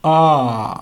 • Its vowel height is near-open, also known as near-low, which means the tongue is positioned similarly to an open vowel, but is slightly more constricted – that is, the tongue is positioned similarly to a low vowel, but slightly higher.
• Its vowel backness is central, which means the tongue is positioned halfway between a front vowel and a back vowel.
listen) is transcribed as ⟨ɐ̹⟩.
Near-open_central_rounded_vowel.ogg.mp3